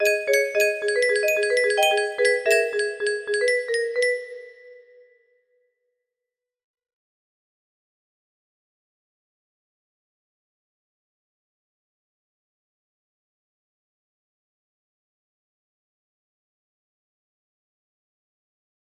melody music box melody